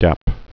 (dăp)